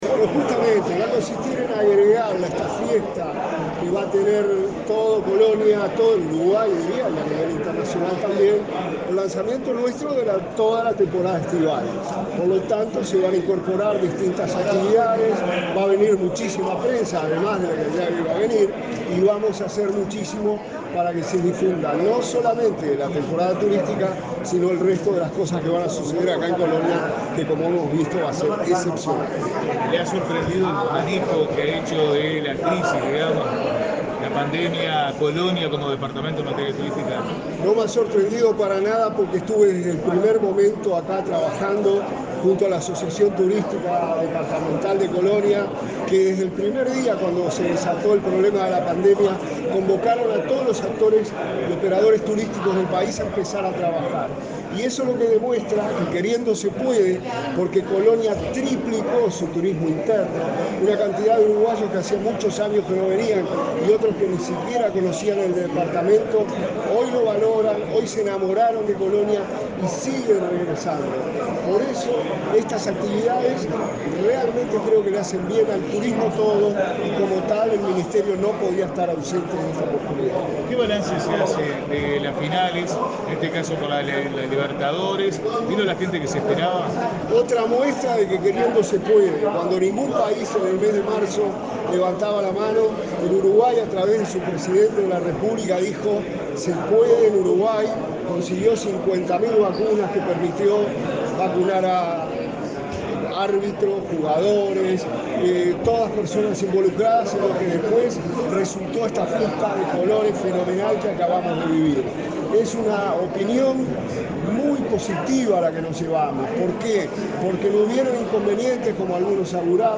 Palabras del ministro interino de Turismo, Remo Monzeglio
Este lunes 29, el ministro interino de Turismo, Remo Monzeglio, participó en Colonia de una conferencia sobre la reapertura de la Plaza de Toros Real